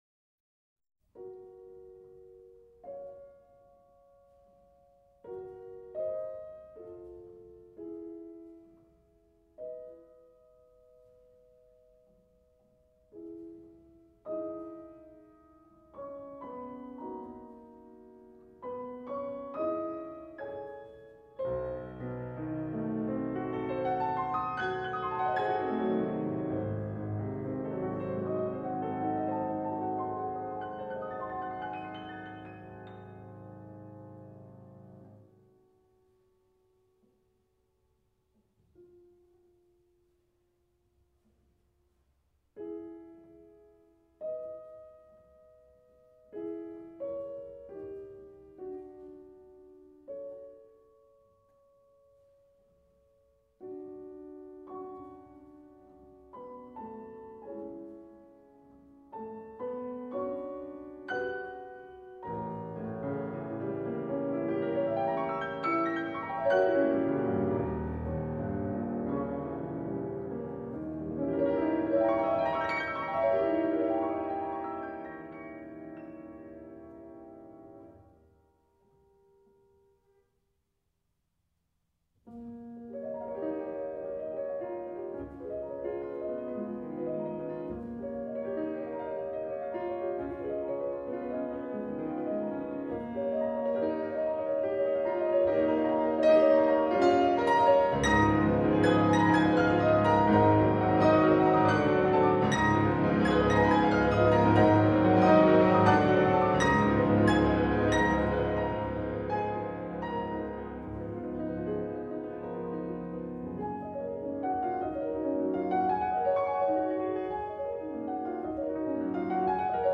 for Harpe, Flute, Clarinet and String Quartet